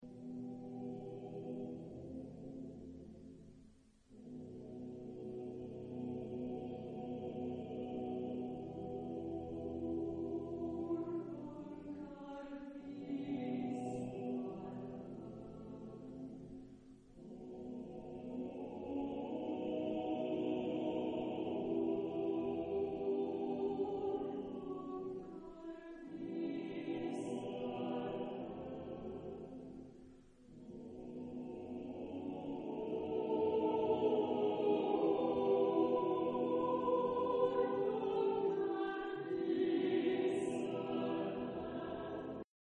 Epoque: 20th century
Type of Choir: SSATBB  (6 voices )